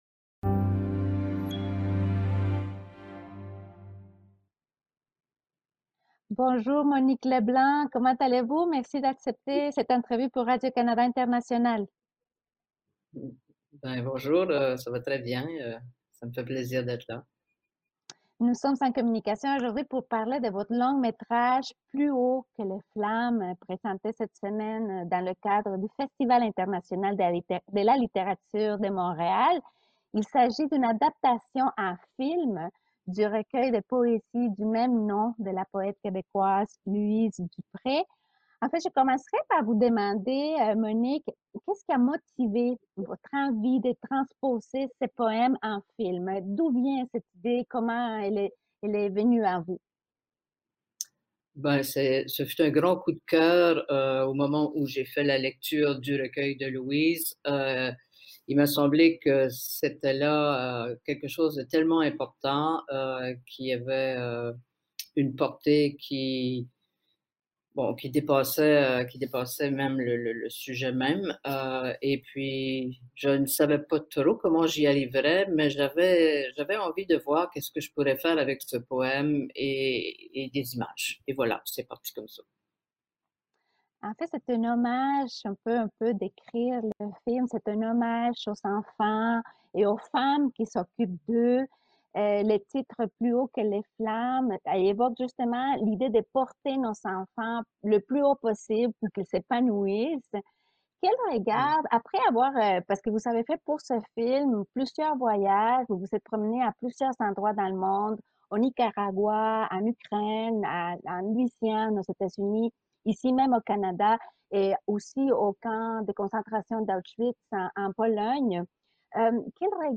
Dans l’entretien, elle partage, entre autres, le regard qu’elle porte sur cette génération d’enfants et de grands-parents, en Acadie, au Canada et dans les endroits qu’elle a visités pour réaliser son film : le Nicaragua, l’Ukraine, la Louisiane aux États-Unis, la Pologne où elle a visité notamment le camp de concentration d’Auschwitz.